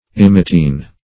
Search Result for " emetine" : The Collaborative International Dictionary of English v.0.48: Emetine \Em"e*tine\ (?; 104), n. [See Emetic .]